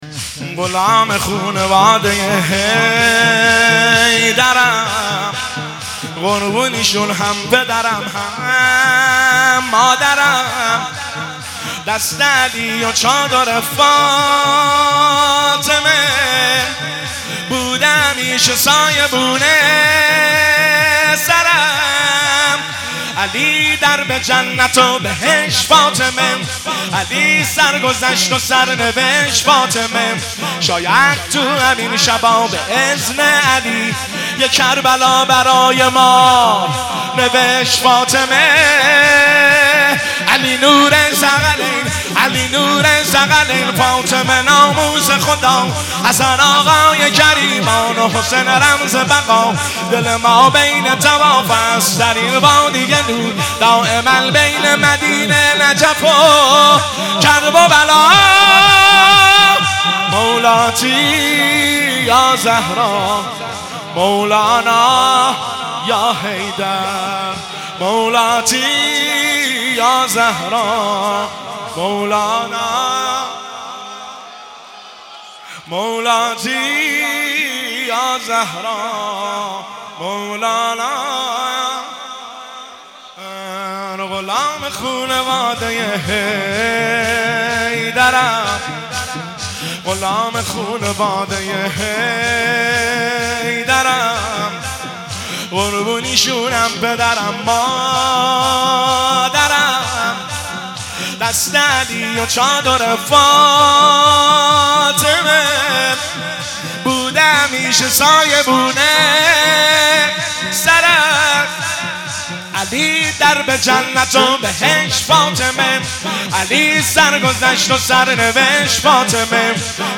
غلام خونواده حیدرم - شور شب دوم فاطمیه 1403